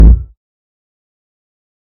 damage_small.ogg